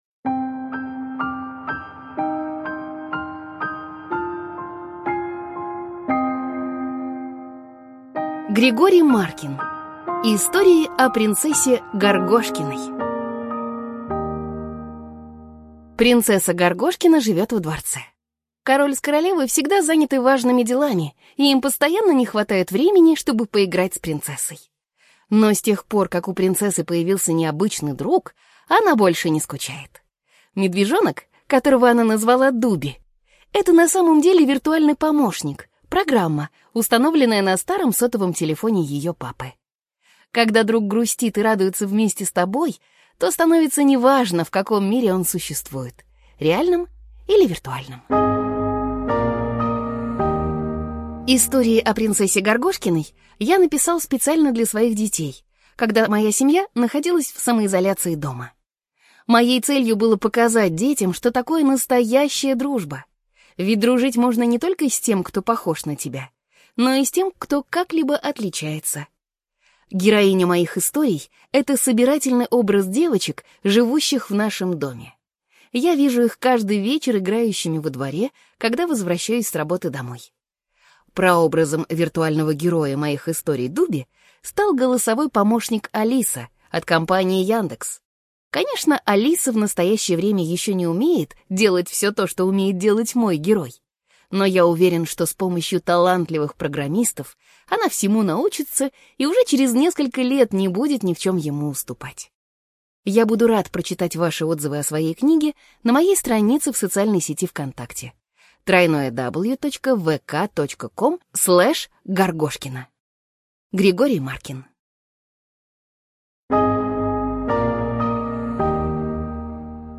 Истории о принцессе Горгошкиной - аудиосказка Маркина - слушать онлайн